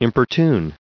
1603_importune.ogg